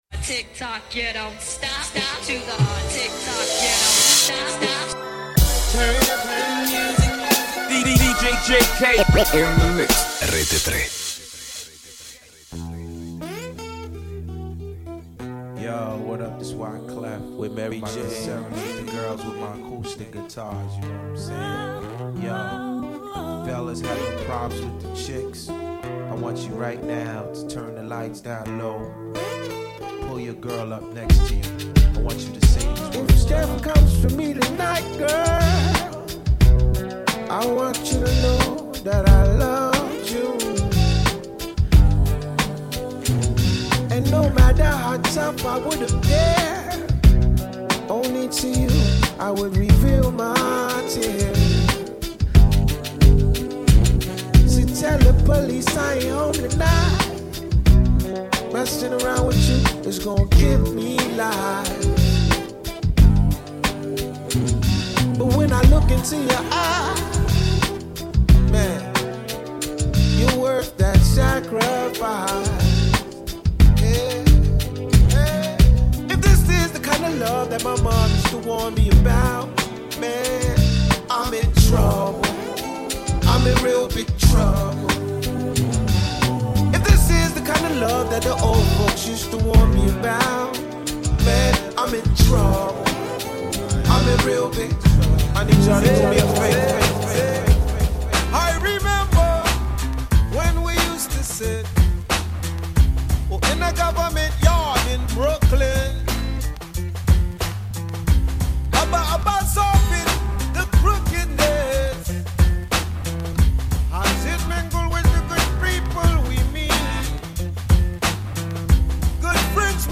BLACK RAP / HIP-HOP